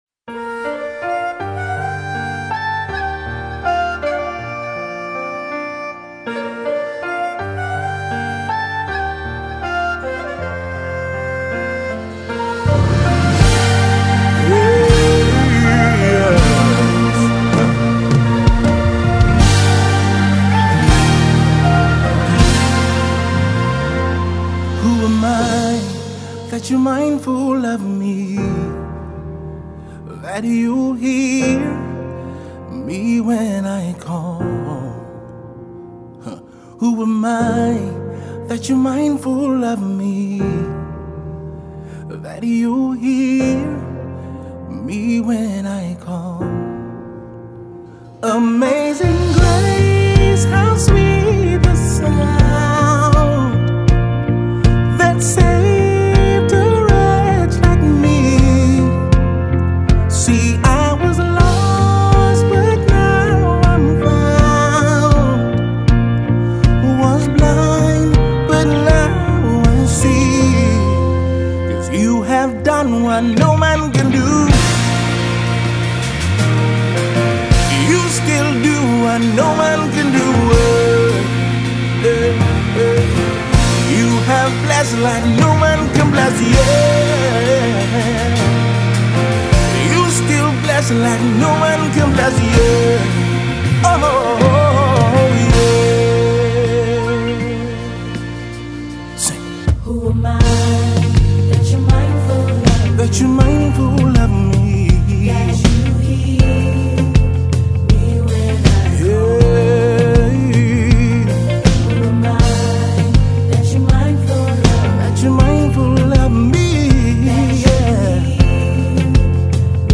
Vocal coach and Veteran gospel singer-songwriter
worship tune